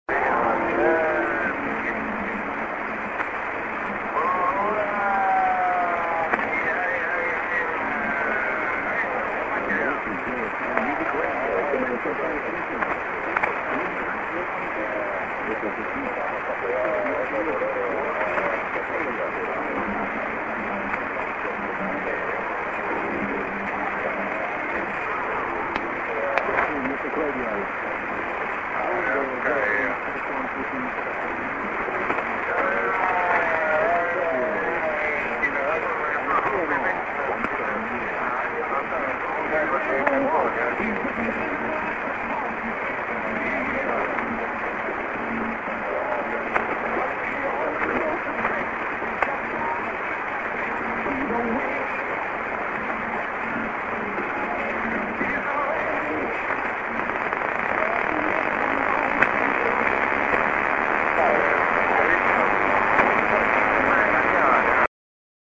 04/02/22 23:00 9,290　 　 Low&QRM
s/on ID->music 　業務局がかぶっています。最後にノイズが入っています。信号は弱いです。